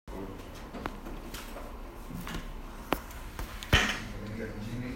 13º. Sessão Ordinária